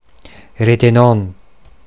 Ladinisch-mundartliche Form
[reteˈnɔn]
Fassaner Variante.
Retenón_Mundart.mp3